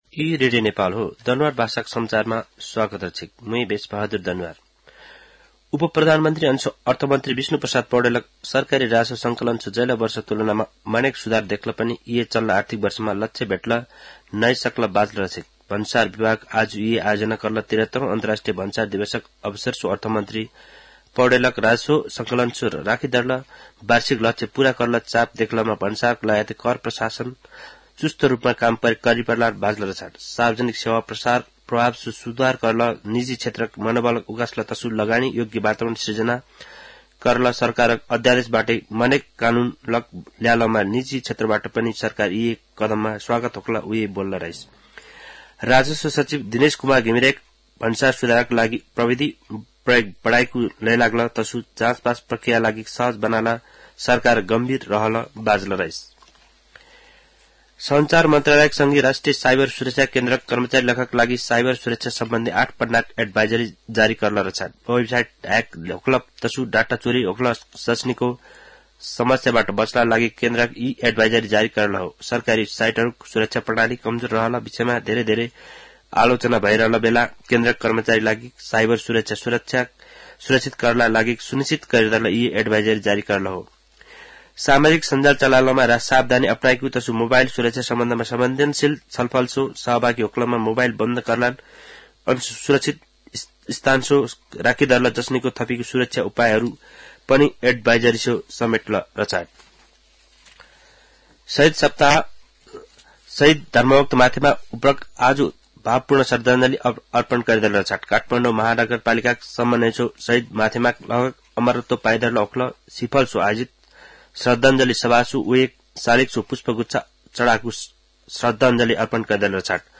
दनुवार भाषामा समाचार : १४ माघ , २०८१
Danuwar-news-9.mp3